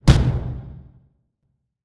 artillery.ogg